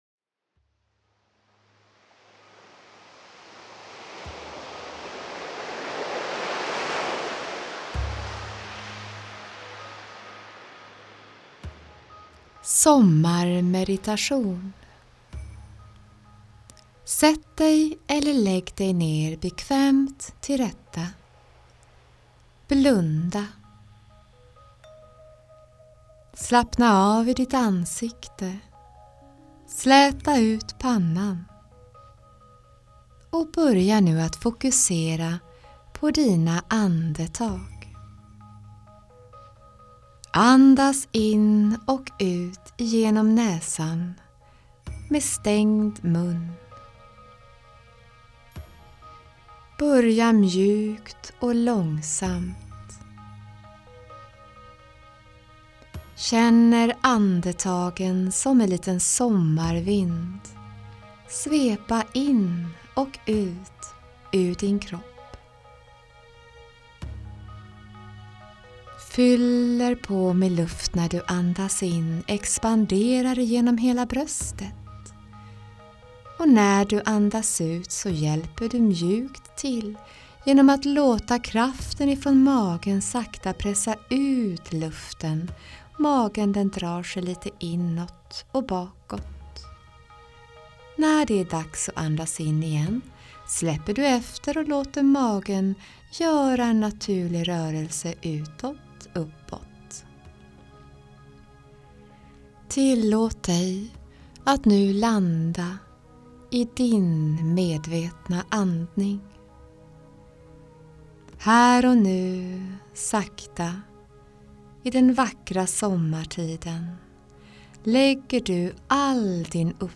En guidad sommarmeditation för meditativa stunder utomhus
Här har jag mixat andningsövningar med guidad meditation och tid för reflektion.